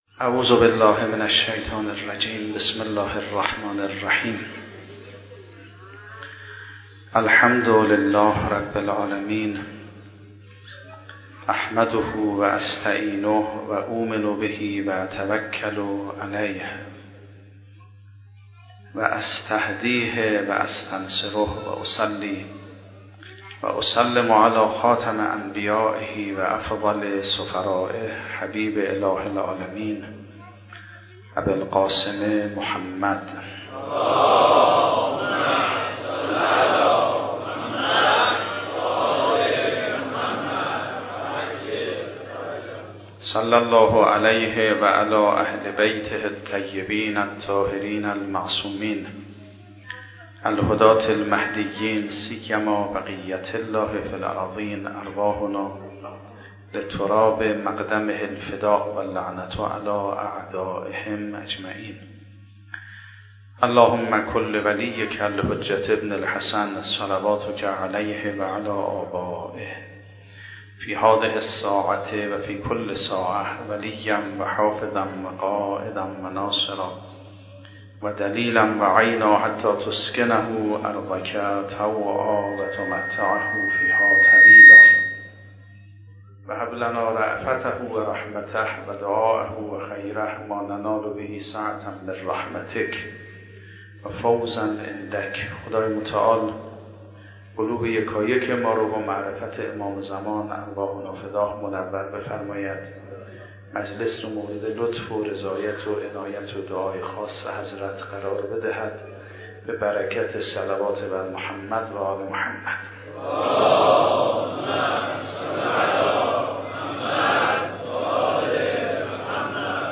شب سوم محرم 96 - هیئت ثار الله - سخنرانی